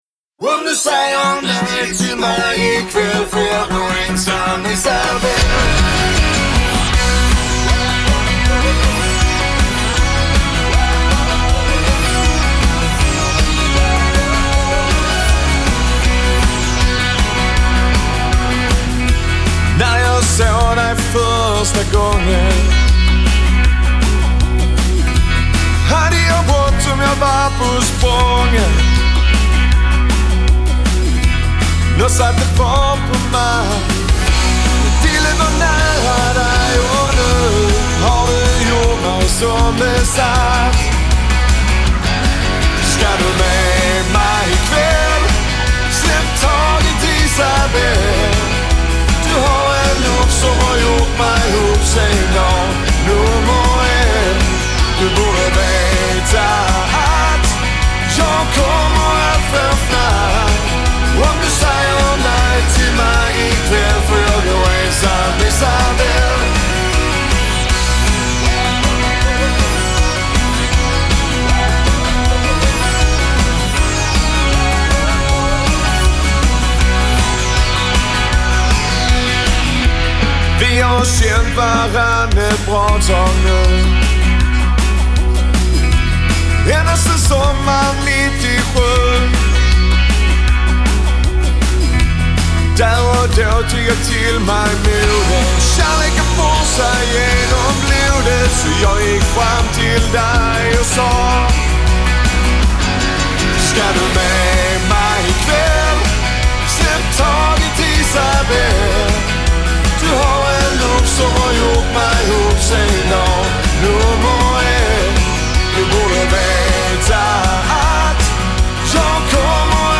Väletablerat coverband som utgår från Skåne.